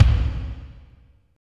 36.03 KICK.wav